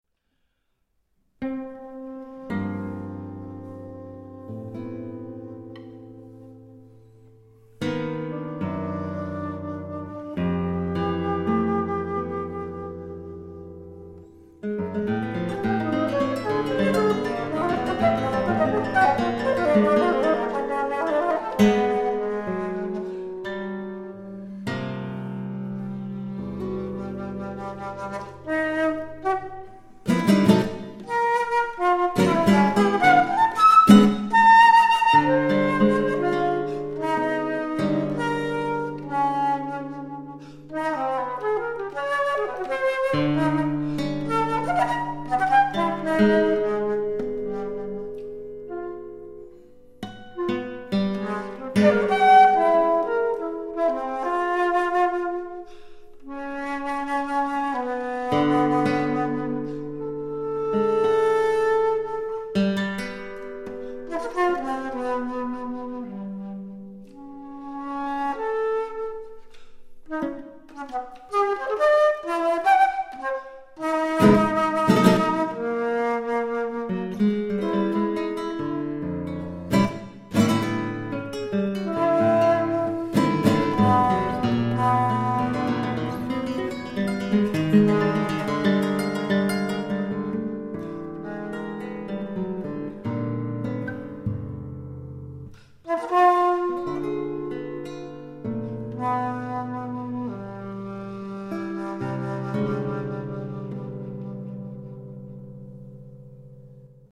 für Altflöte und Gitarre, 11'